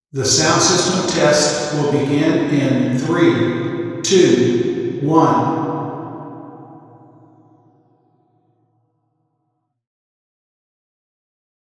Figure 5 - This room exhibits significant modal and reverberant behavior.
The RIR of an omnidirectional sound source was gathered at 3 test positions using an omnidirectional microphone (Figure 6). Note that the distances are log-spaced.
↑ Speech at TP1
RIR_TP1_5m_321Intro_XYSTEREO.wav